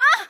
damage_3.wav